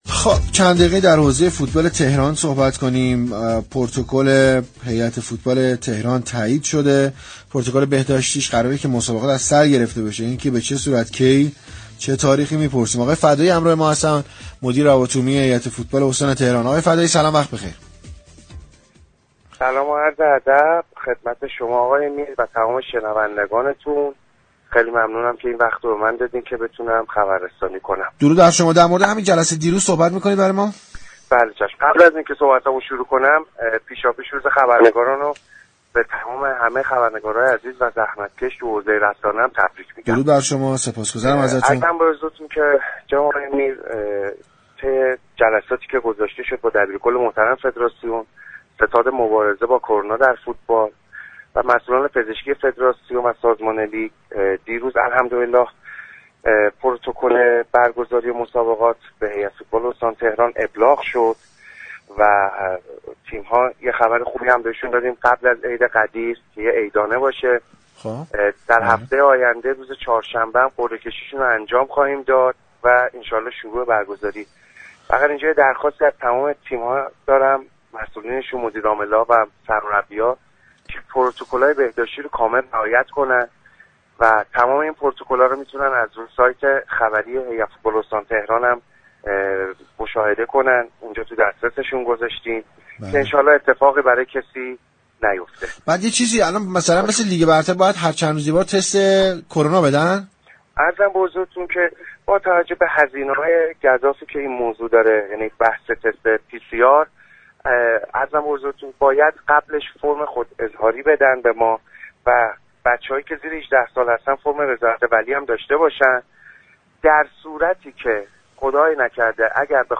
در گفتگو با تهران ورزشی رادیو تهران